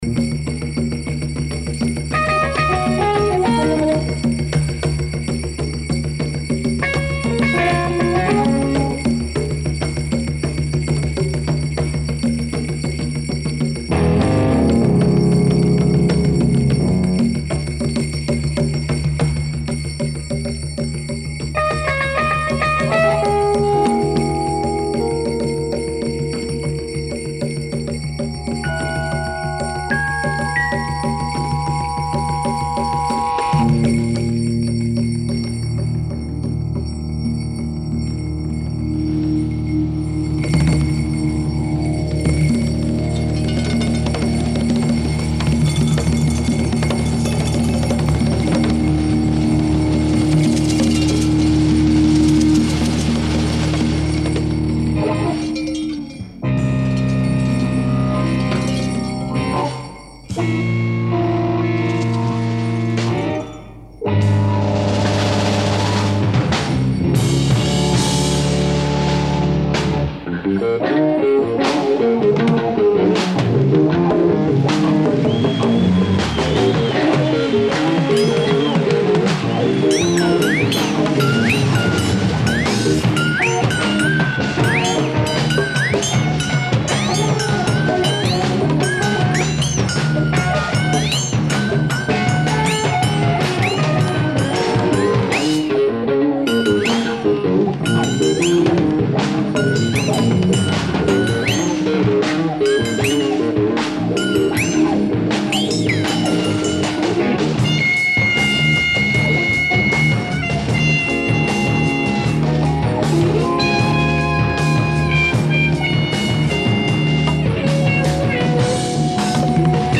ライブ・アット・フィルハーモニー、ベルリン、ドイツ 11/06/1971
※試聴用に実際より音質を落としています。